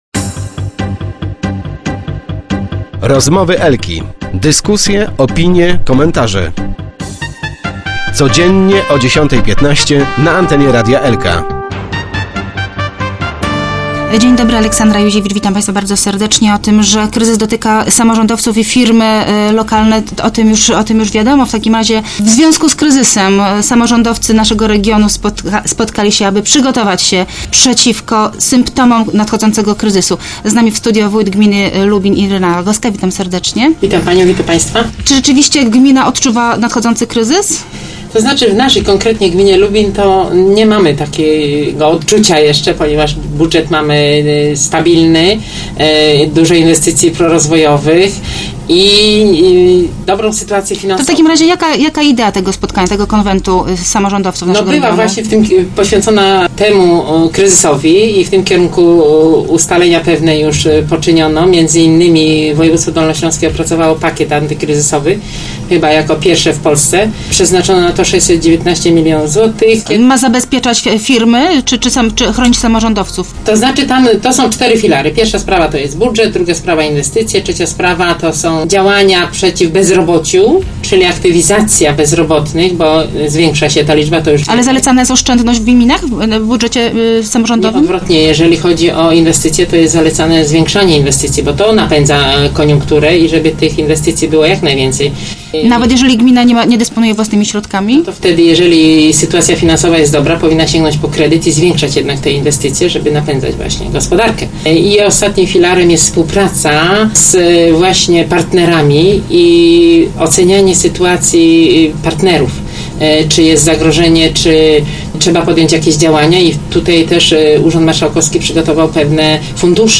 0310_rogowska_irena.jpgLubin. Samorządowcy regionu spotkali się, aby wspólnie dyskutować nad ewentualnymi skutkami kryzysu gospodarczego. - My go jeszcze nie odczuwamy - mówiła w dzisiejszych Rozmowach Elki Irena Rogowska, wójt gminy wiejskiej Lubin /na zdjęciu/.